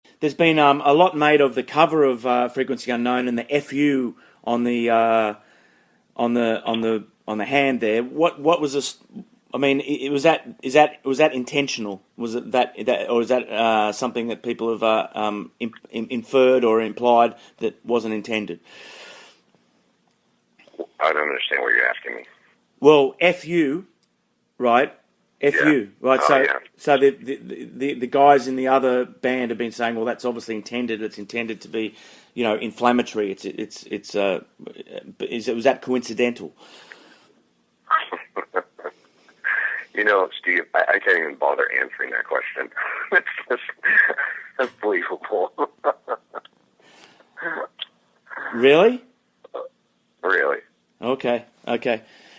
This one of the most stilted and awkward rock interviews I’ve ever done, as you can hear below.
Geoff-Tate-interview.mp3